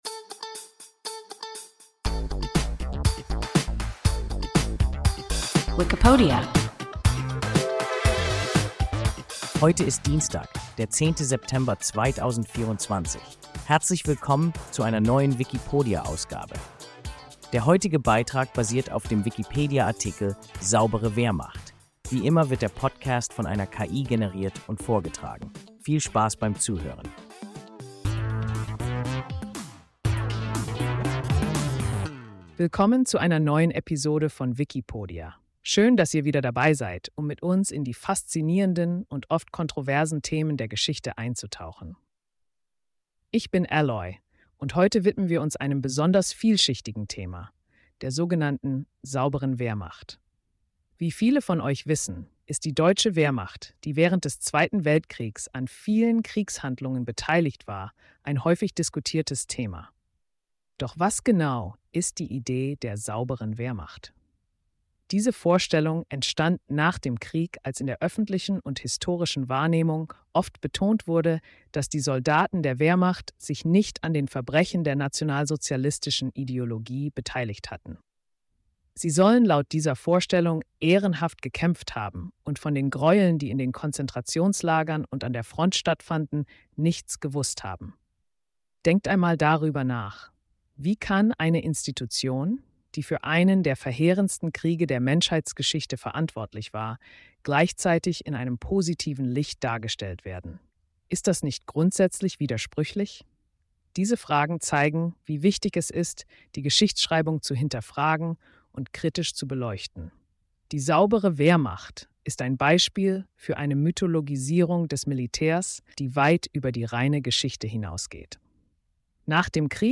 Saubere Wehrmacht – WIKIPODIA – ein KI Podcast